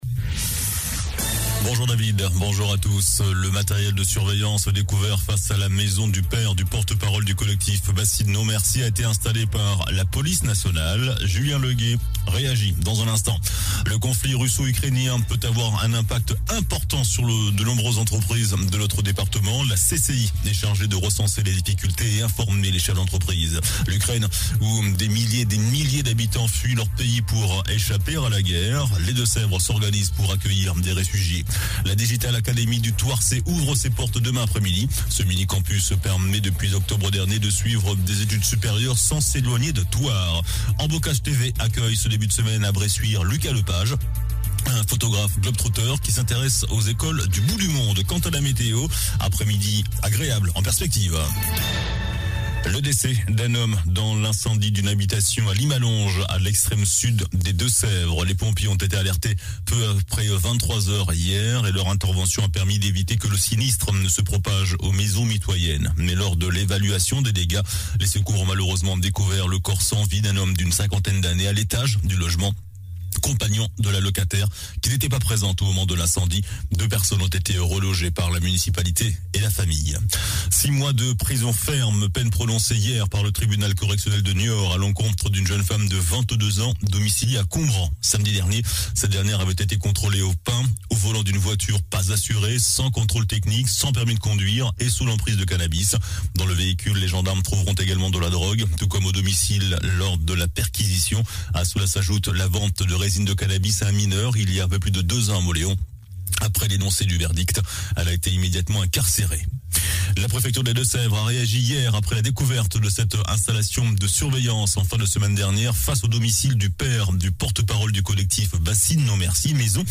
JOURNAL DU MARDI 22 MARS ( MIDI )